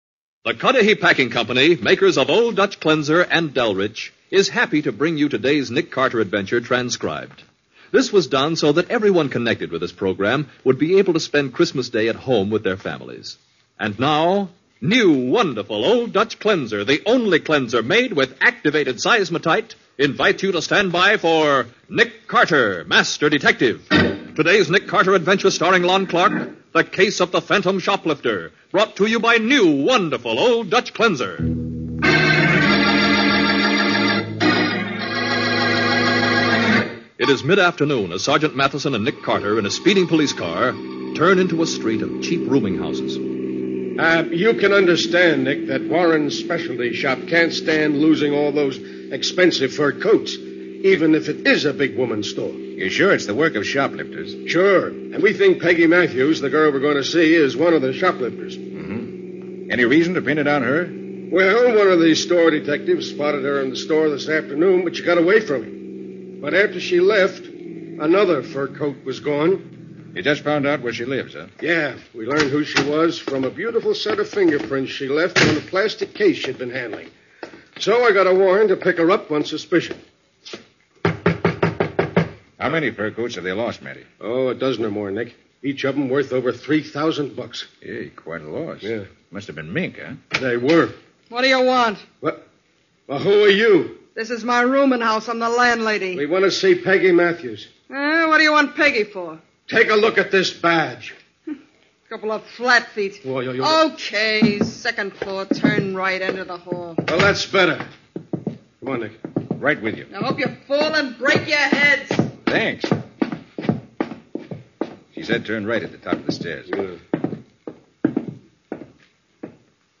Nick Carter, Master Detective was a Mutual radio crime drama based on tales of the fictional private detective Nick Carter from Street & Smith's dime novels and pulp magazines.